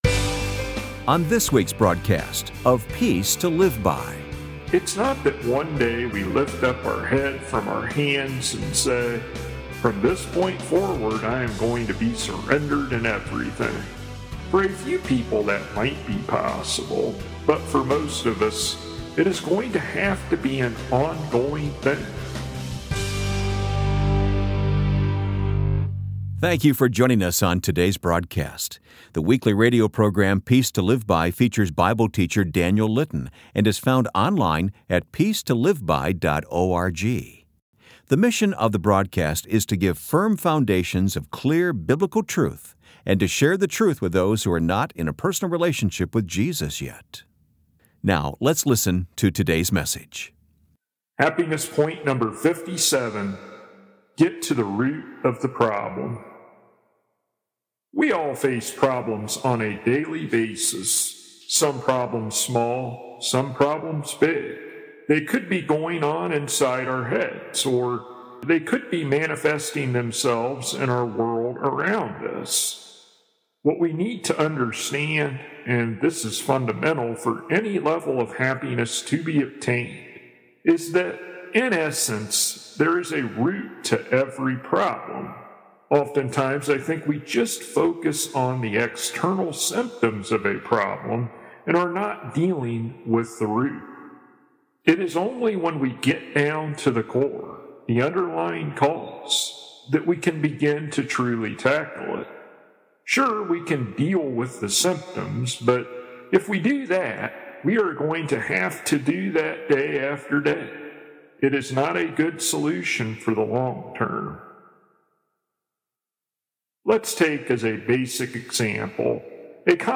[Transcript may not match broadcasted sermon word for word] Happiness Point #57